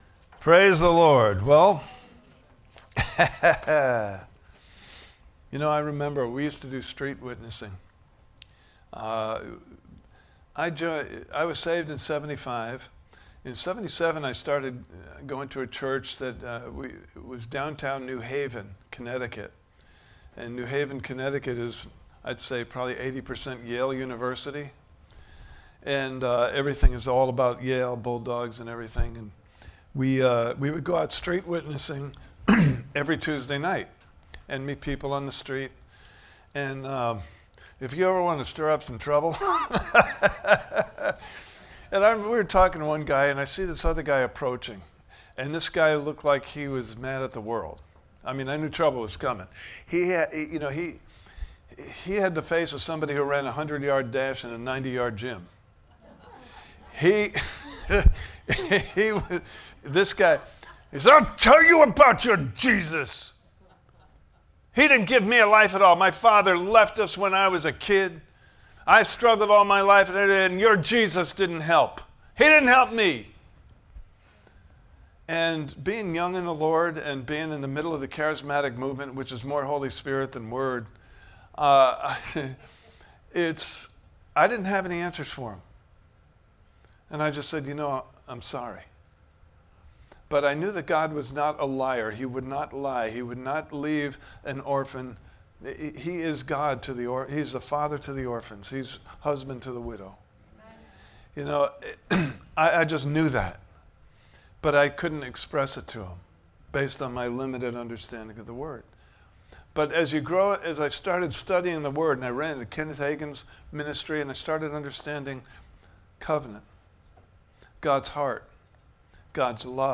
Service Type: Sunday Morning Service « Building Your House (Video) Part 1: As For Me …